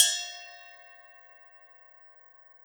Cymbol Shard 01.wav